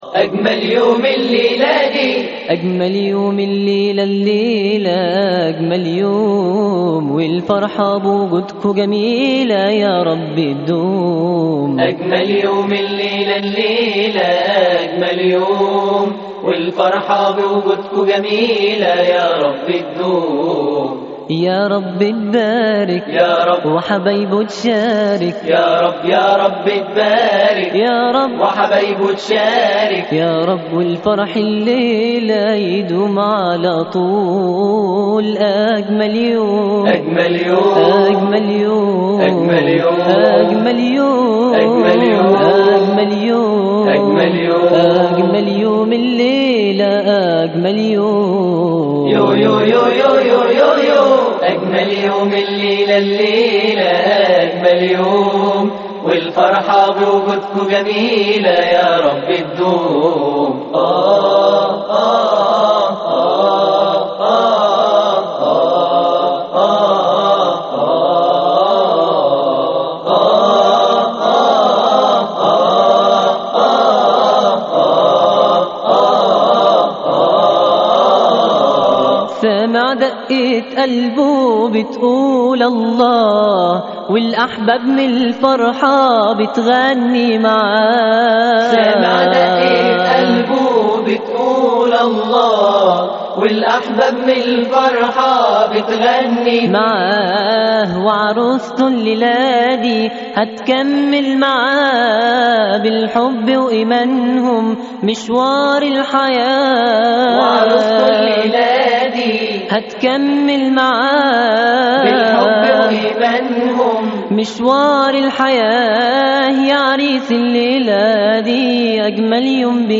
أناشيد ونغمات